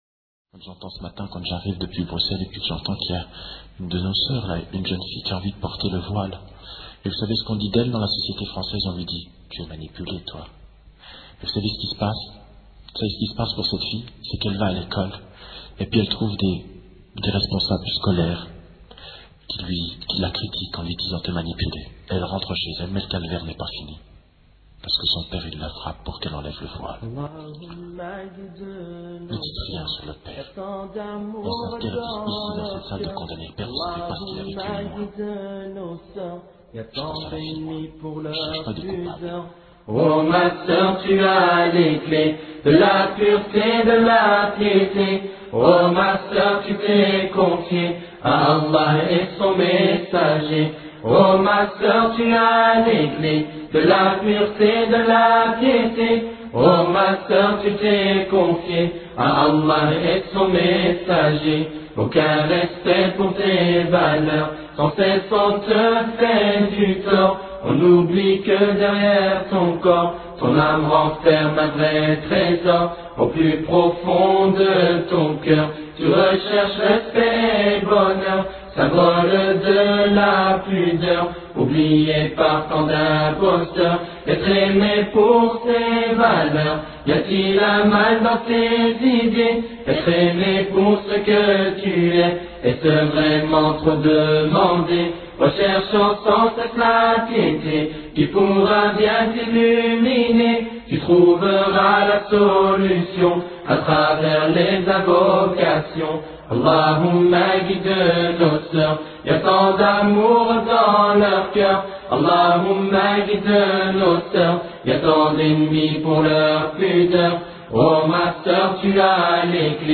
Repentir orateur: quelques jeunes période de temps: 00:00:00